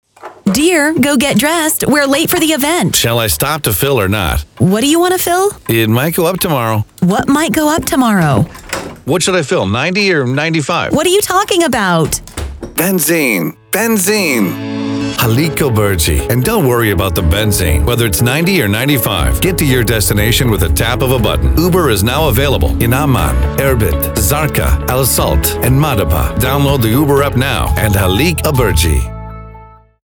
Uber-ad.mp3